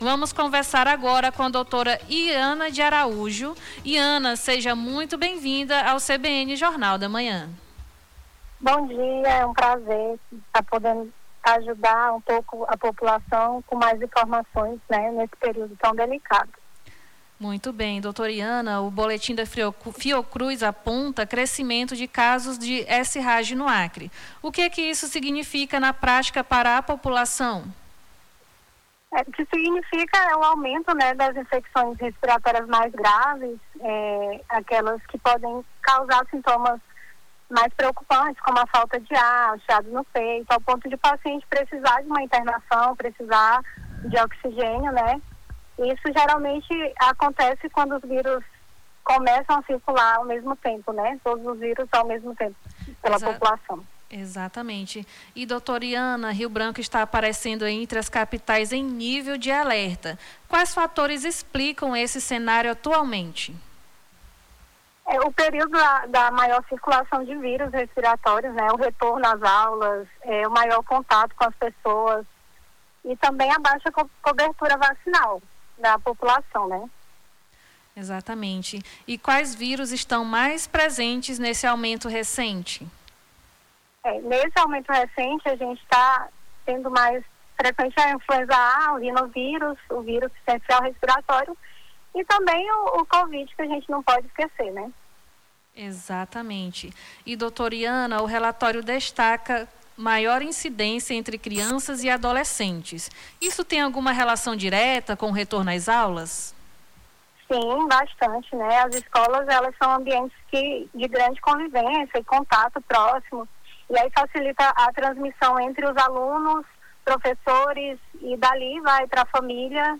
Nome do Artista - CENSURA - ENTREVISTA SÍNDROMES GRIPAIS - 13-03-26.mp3